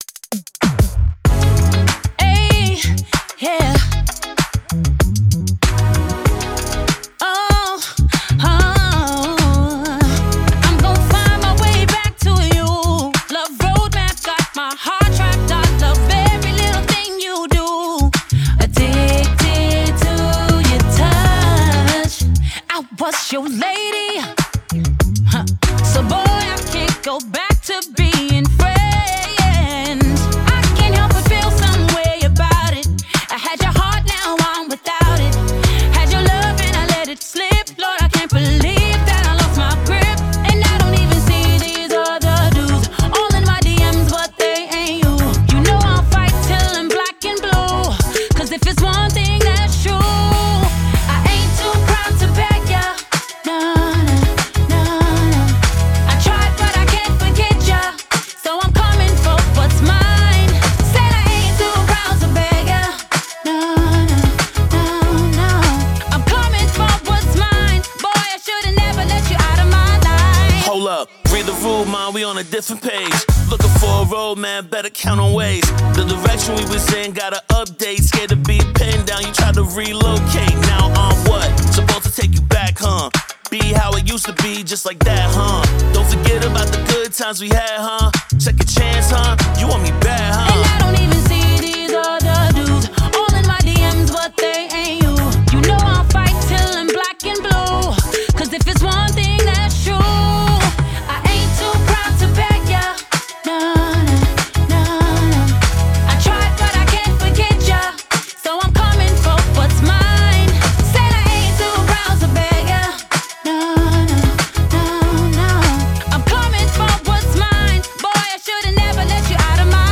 Songwriter, Vocalist
R&B, Pop
C#min